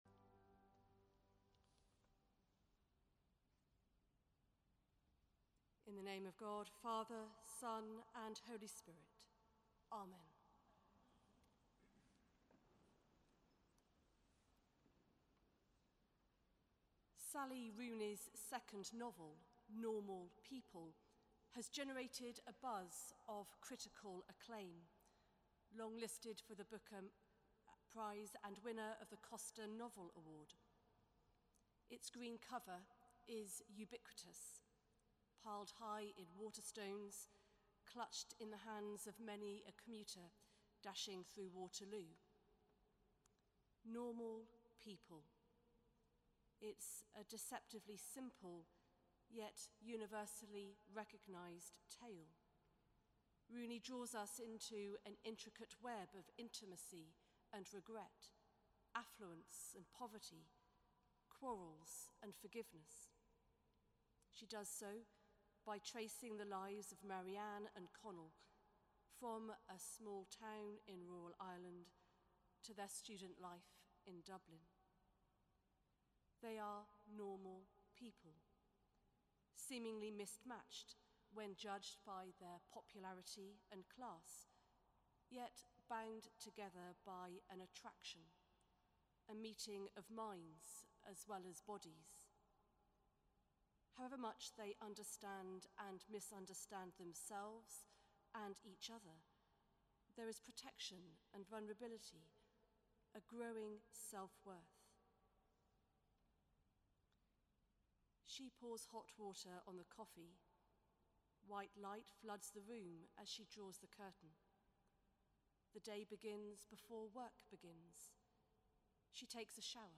Sermon: Choral Evensong - 27 January 2019